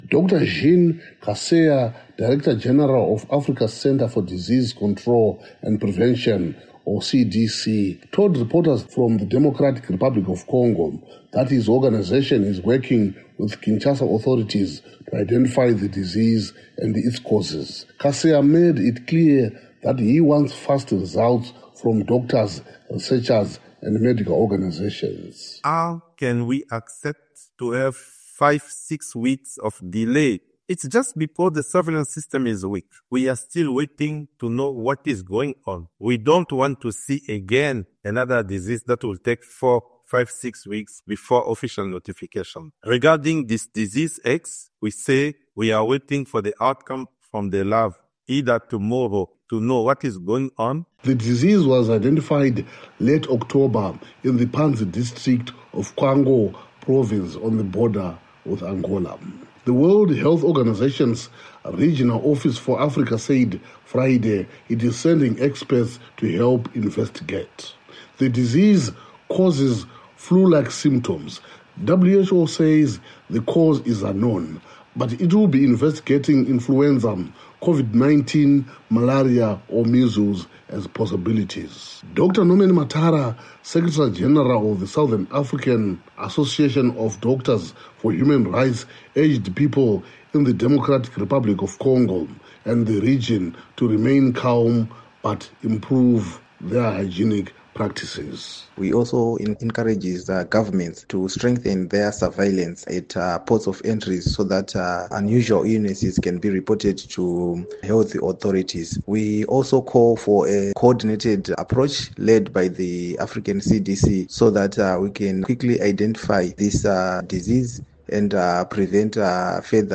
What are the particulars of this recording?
reports from Zimbabwe’s capital